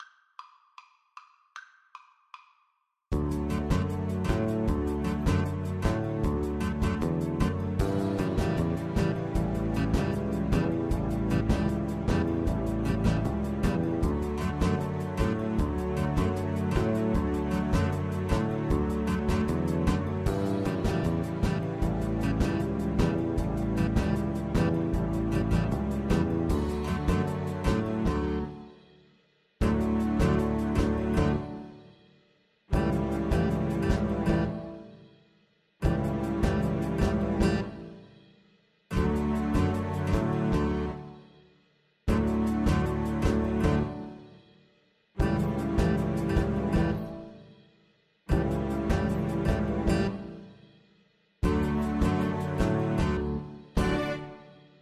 4/4 (View more 4/4 Music)
Presto (View more music marked Presto)
Arrangement for Cello and Piano